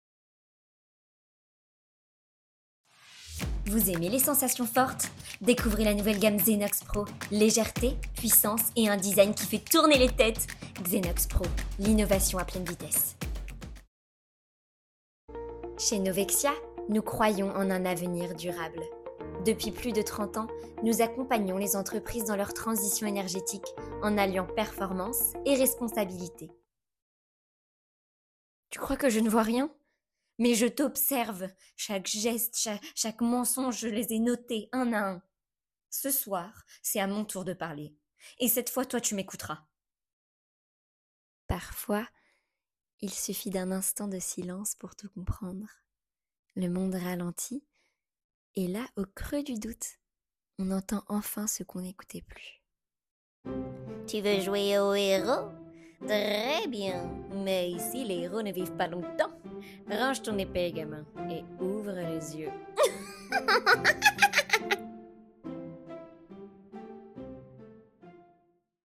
Bande démo Voix